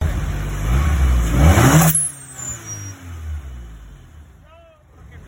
If anyone wanted to know the sr20 sounds like.